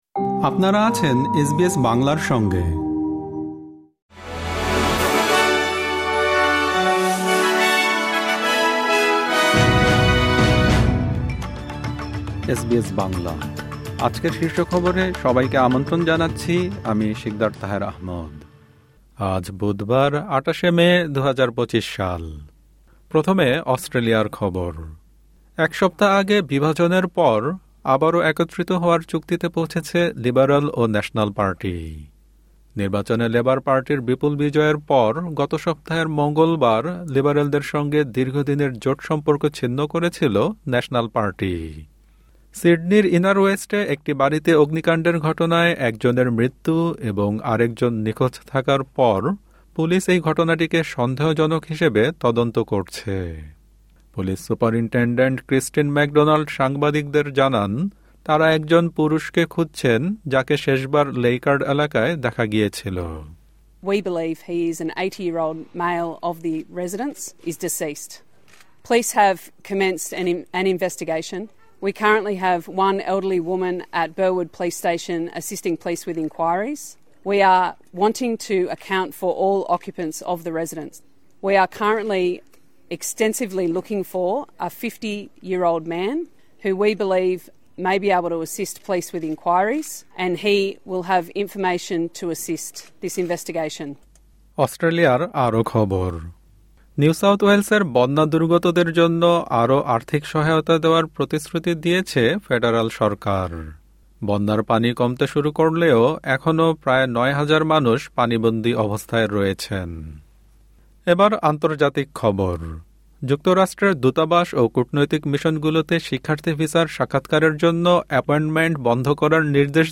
এসবিএস বাংলা শীর্ষ খবর: ২৮ মে, ২০২৫